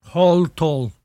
[hohl-tohl]